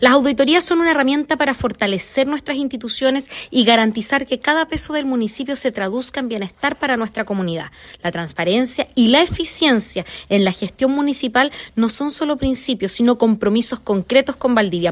La alcaldesa Carla Amtmann señaló que la auditoría busca garantizar los estándares de transparencia y eficiencia de los recursos municipales y que lo anterior se traduzca en bienestar de los habitantes.